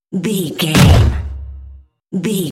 Dramatic hit deep electronic wood
Sound Effects
Atonal
heavy
intense
dark
aggressive
the trailer effect